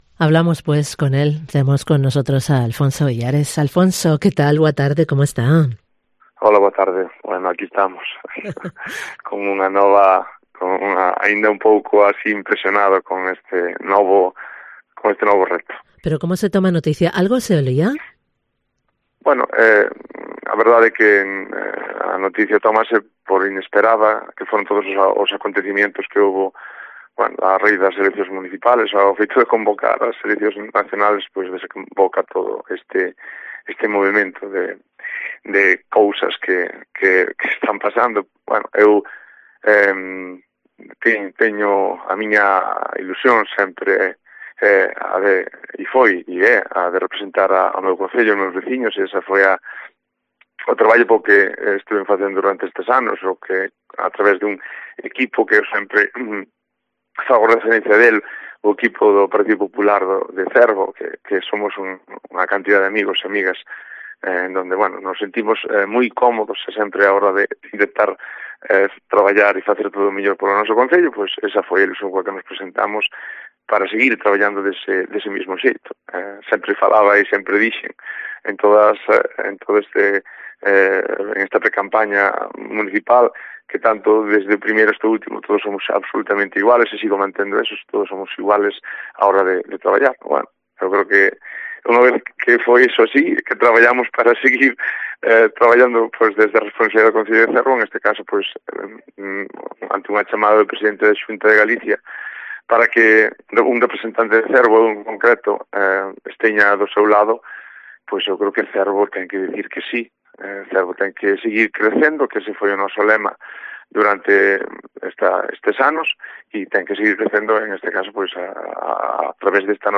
Entrevistamos a Alfonso Villares, nuevo conselleiro de Mar en Galicia